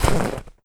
STEPS Snow, Run 20.wav